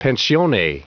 Prononciation du mot pensione en anglais (fichier audio)
Prononciation du mot : pensione